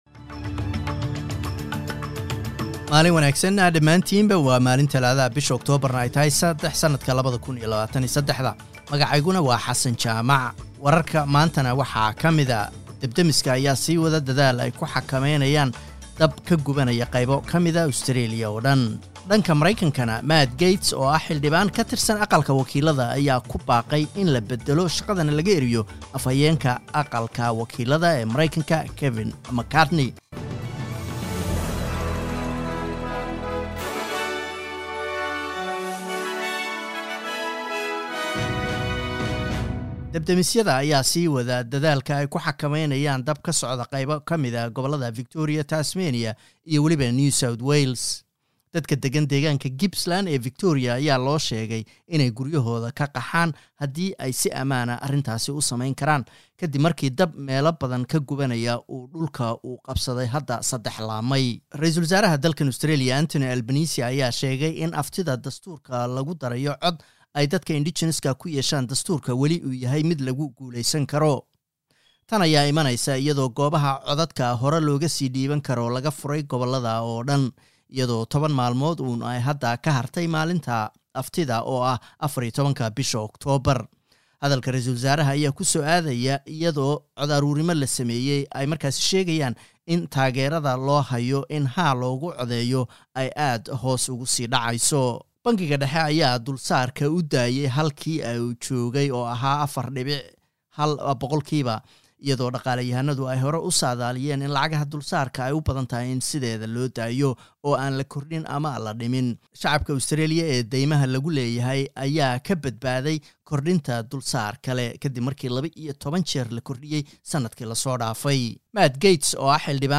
Wararka SBS Somali